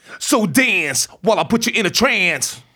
RAPHRASE01.wav